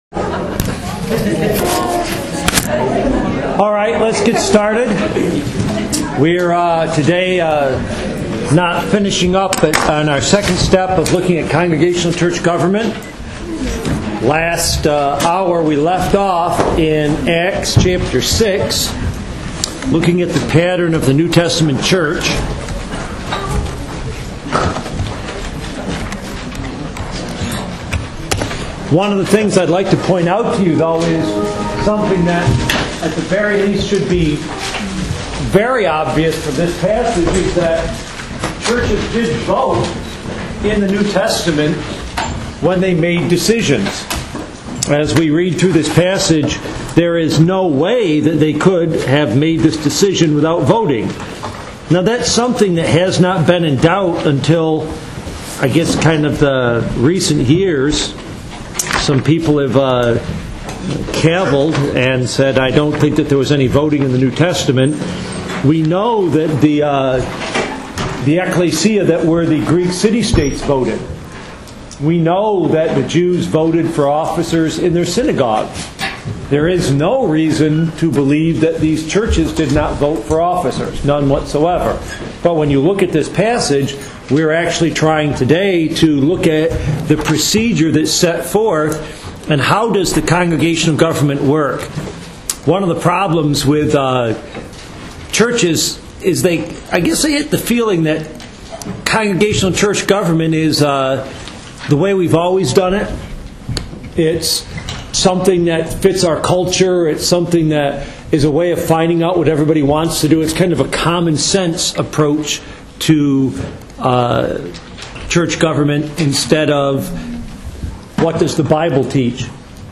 08. Congregational govt lecture 2.mp3